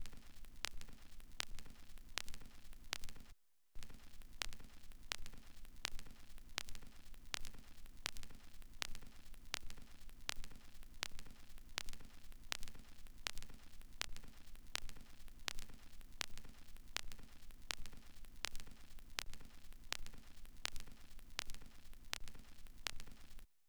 06 vinyl noise.wav